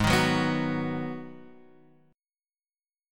G#m7 chord